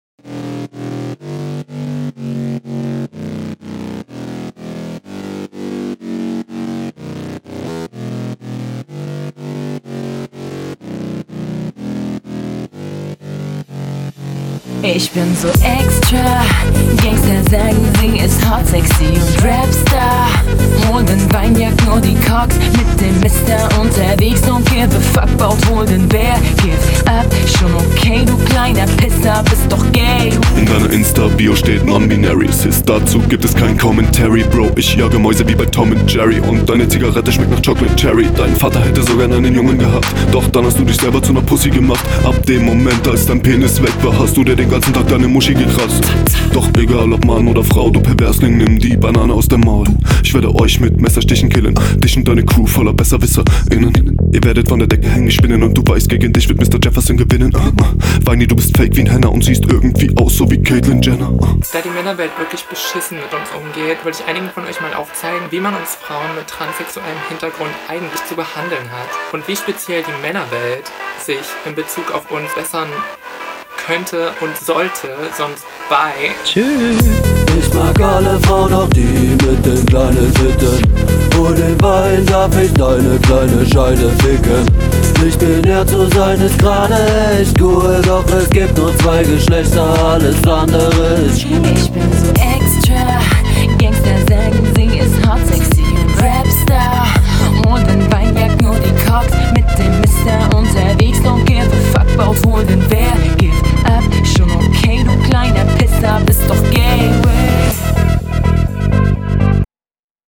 Flow: Der Part ist gut geflowt, sehr angenehm zu hören Text: Lustiger Text, weiß nicht, …